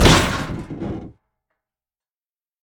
car-metal-impact.ogg